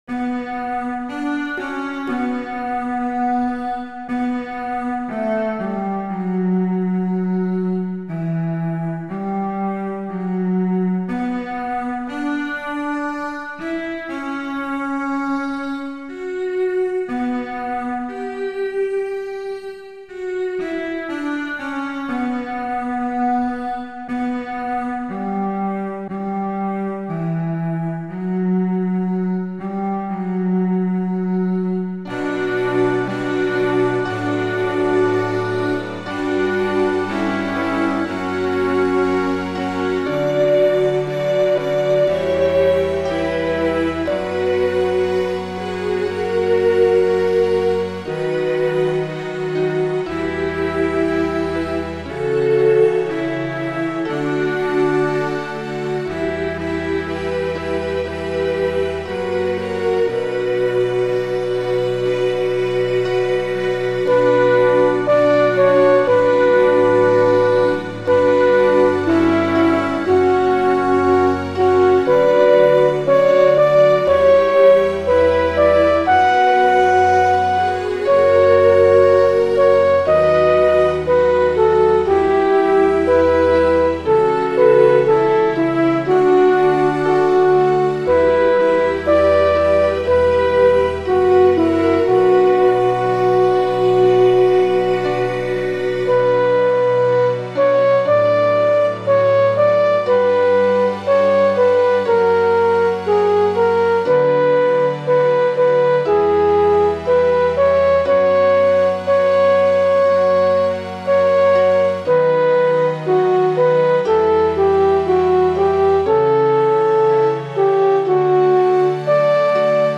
My backing has both melody lines running.